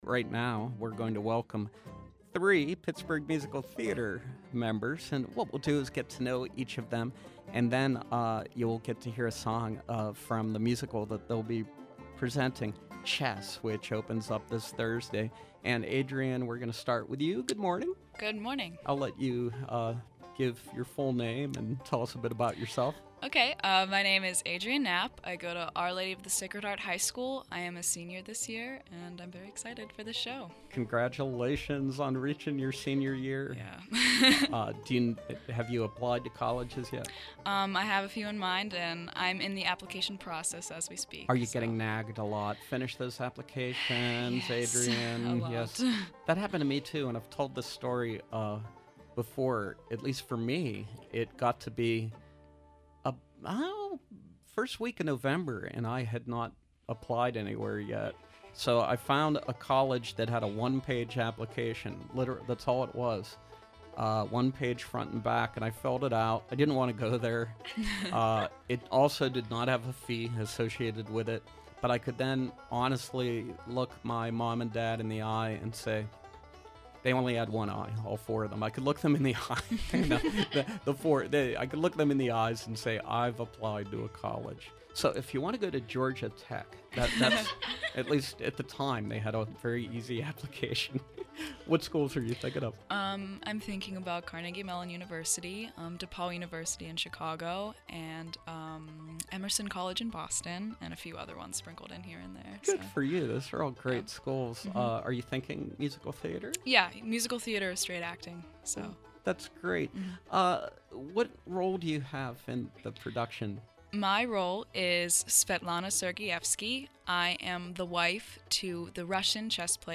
Joining us, cast members of Pittsburgh Musical Theater’s upcoming production of Chess, a rock opera that uses the game of chess, with all its power plays, manipulations and sacrificial pawns, as metaphor for love and life.